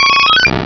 eevee.aif